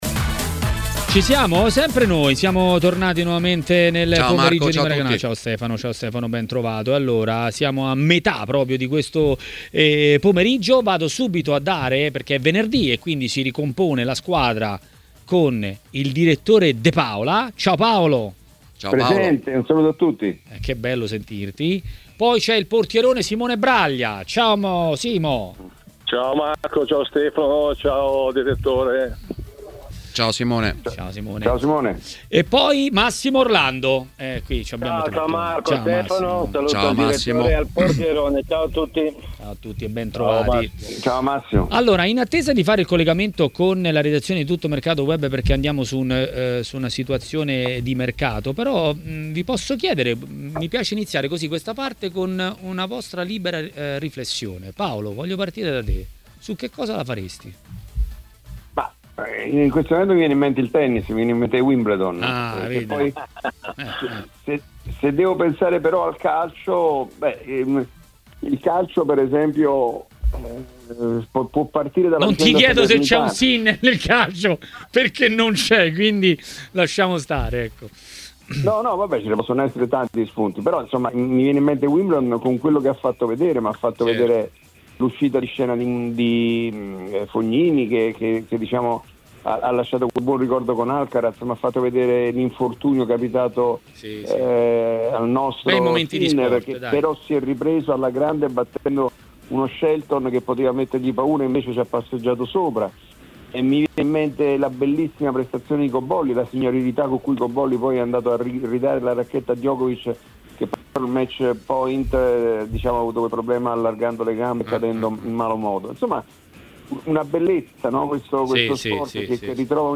Ospite di Maracanà , su TMW Radio